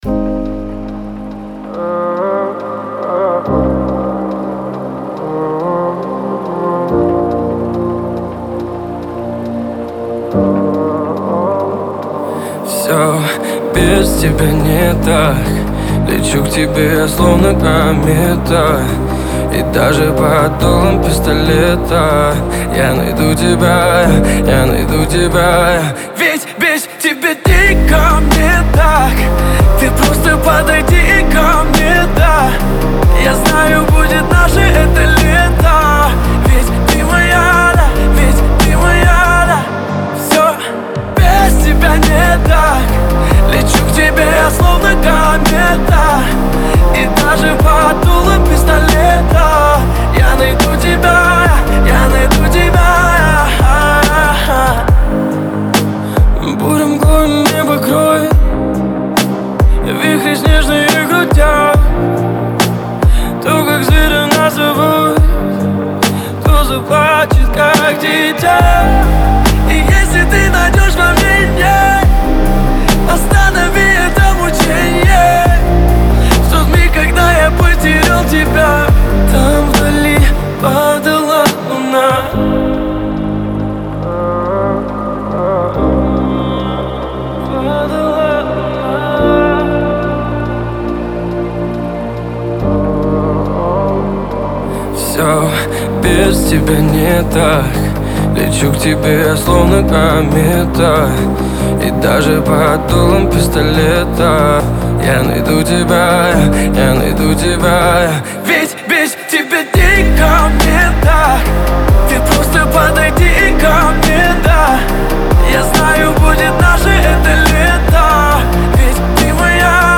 Современные медленные песни
русские медляки